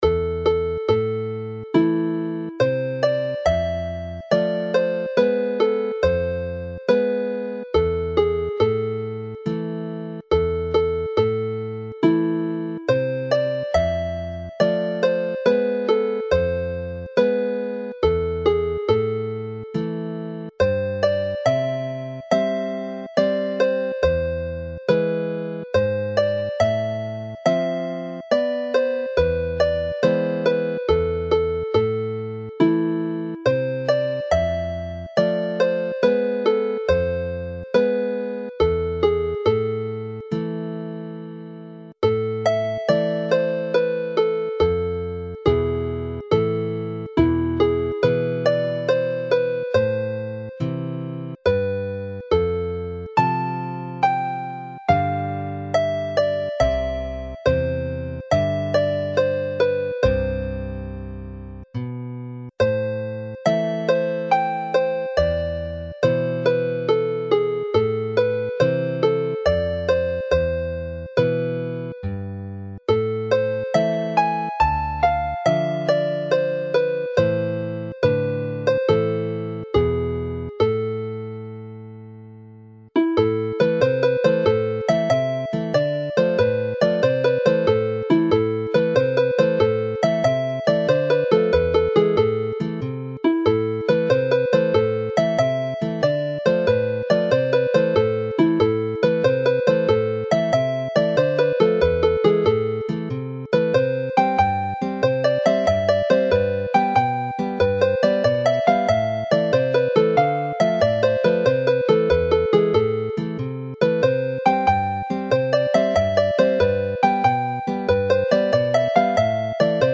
The tunes in this set use the typically Welsh minor scale. All are in A minor and have the tone-and-a-half interval from F natural to G sharp to produce the characterstic feel to the melody. This occurs in part A of the tune which then goes into C major for the second part with a G natural in part B before returning again to the minor key to repeat part A, typical of many Welsh traditional tunes.
The tunes work well with a lever harp which can be set to G# in the lower octave of the melody and G natural in the upper octave.